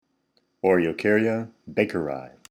Pronunciation/Pronunciación:
O-re-o-cár-ya bà-ke-ri